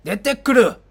Sound effect
罕见语音6.wav